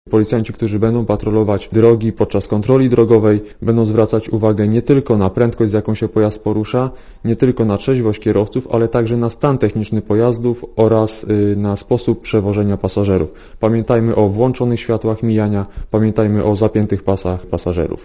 Komentarz audio Nie obyło się jednak zupełnie bez wypadków.